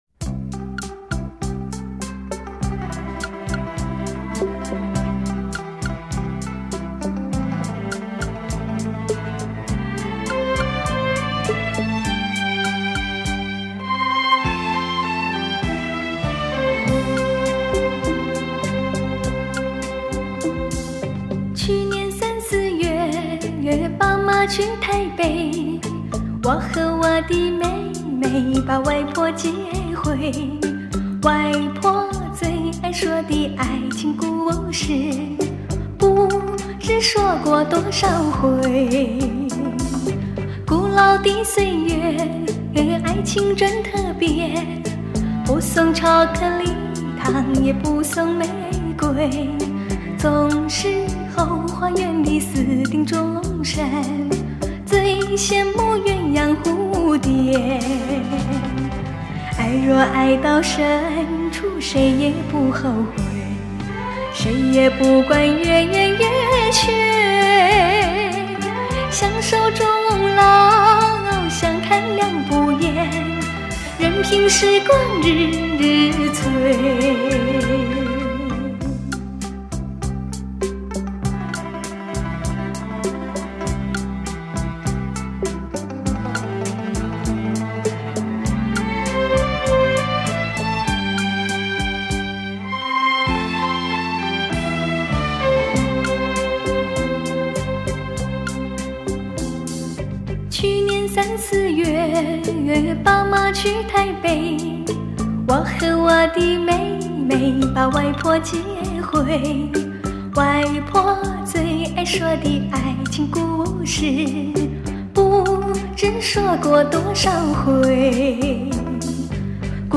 台湾歌手。
擅长中国风味小调歌曲，  并曾二度入围金曲奖